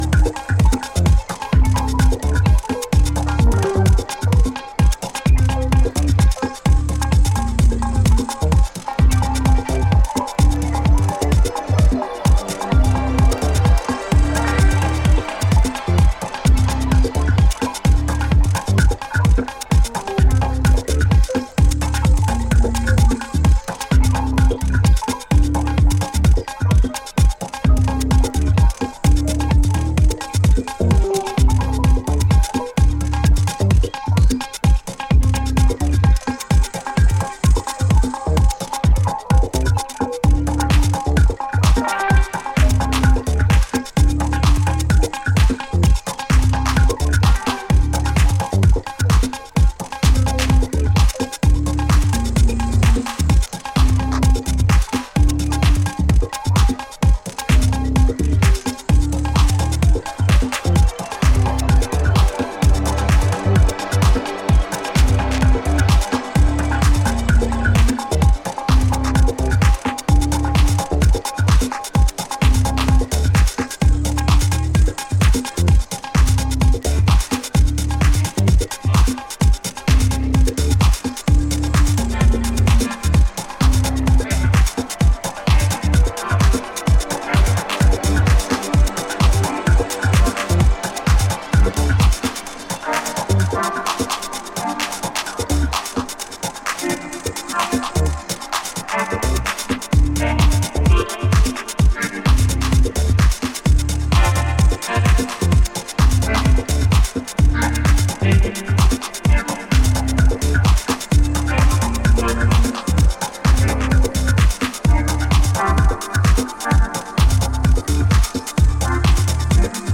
ここでは、抑制の効いたメロディアスでサイケデリックなレイヤーを配し、秀逸なミニマル・テック・ハウスを展開。